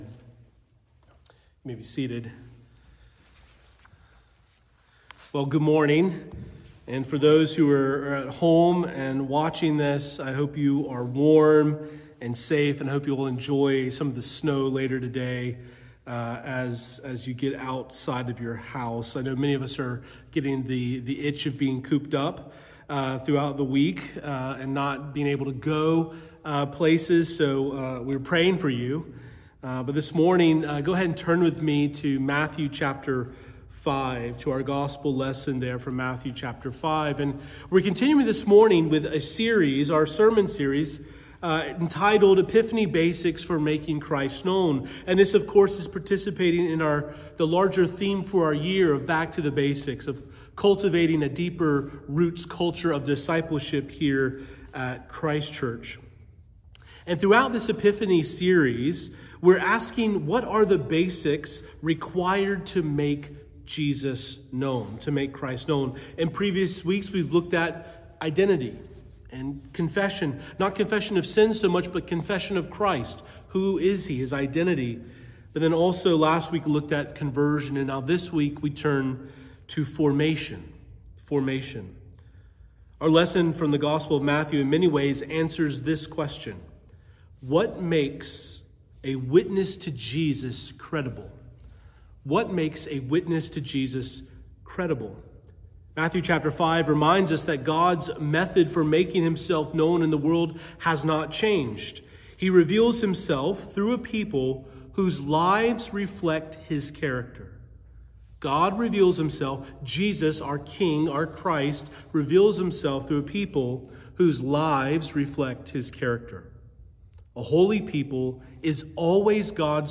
Sermon begins at 13:00 minutes.
Fourth Sunday of Epiphany_ Morning Prayer.mp3